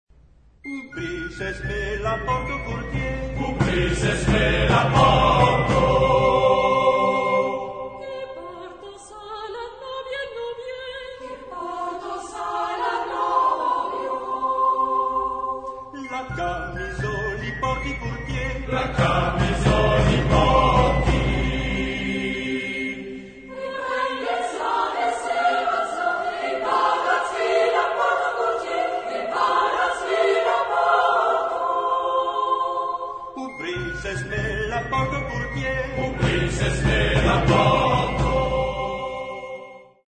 Dialogue de deux choeurs à trois voix égales.
Genre-Style-Forme : Profane ; Populaire
Type de choeur : SMA + SMA  (6 voix égales )
Tonalité : sol mineur